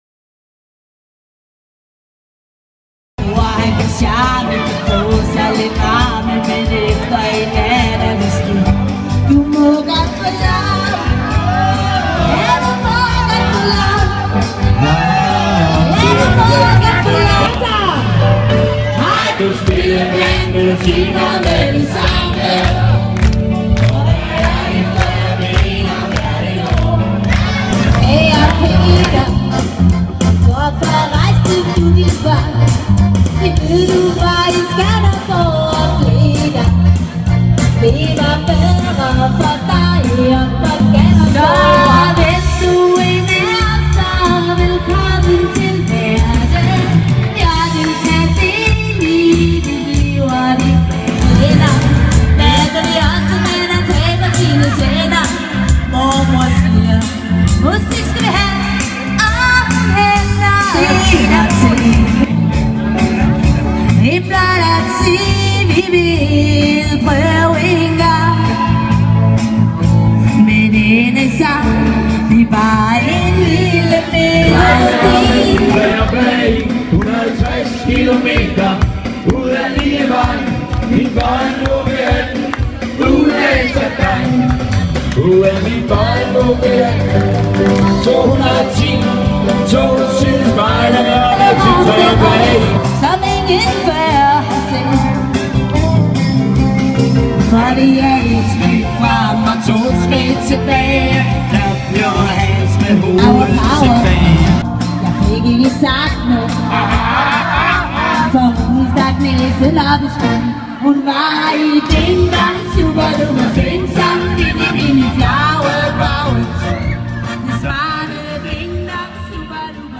Dansktop gennem tiderne
• Coverband
lidt glad musik